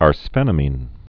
(ärs-fĕnə-mēn)